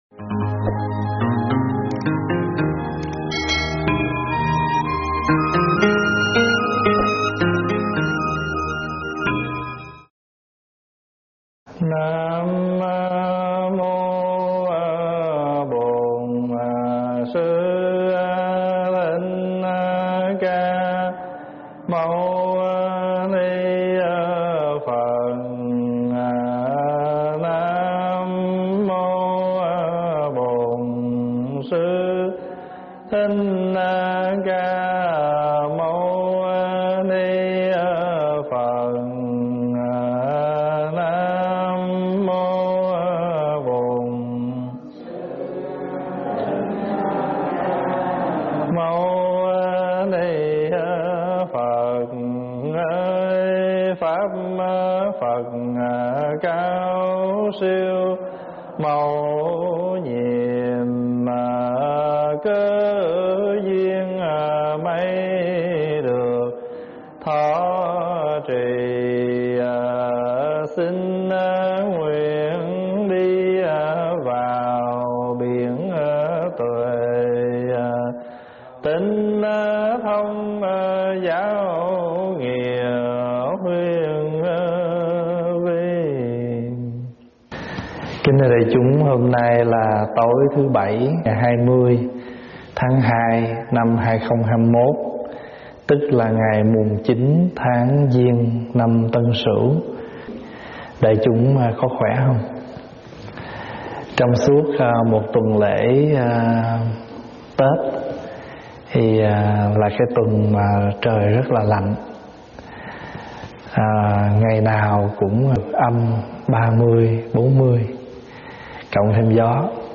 Thuyết pháp Báo Ân Rộng Lớn 24 - ĐĐ.